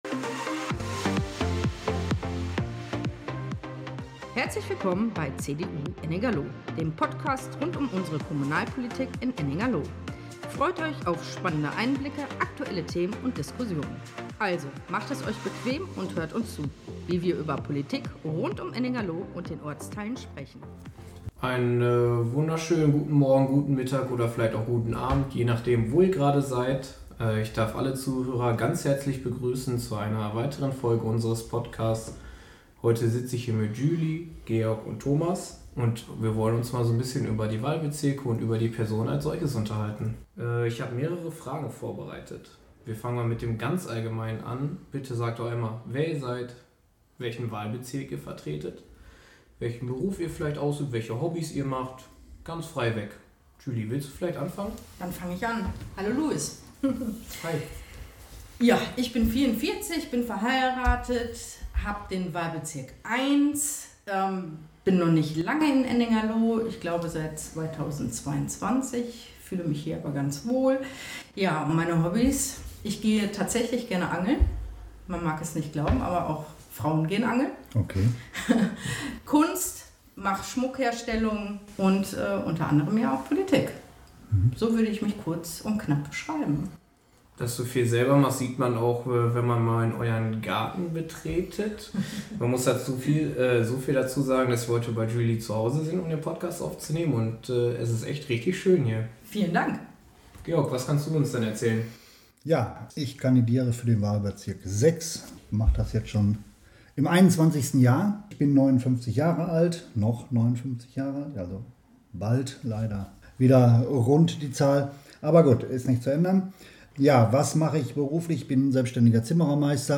Kandidatenvorstellung
Lively-Instrumental Intro und Outro